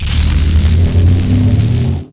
alt_fire.wav